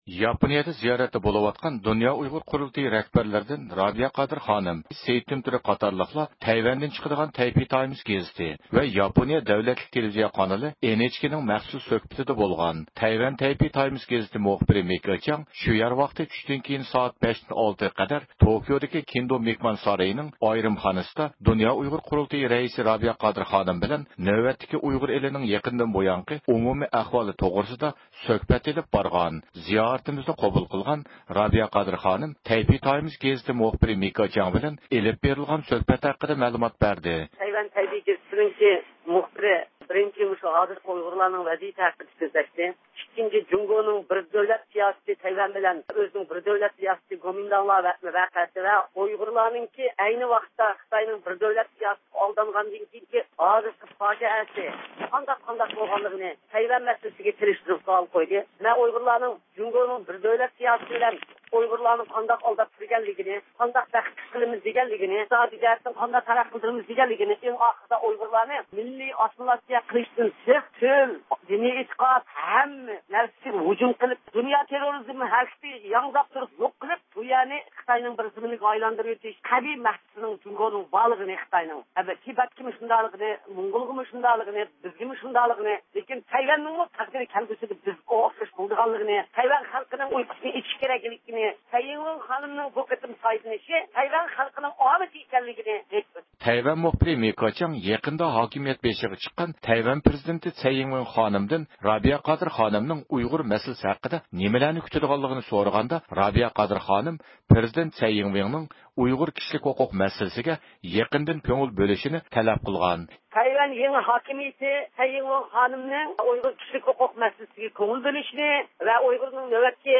زىيارىتىمىزنى قوبۇل قىلغان رابىيە قادىر خانىم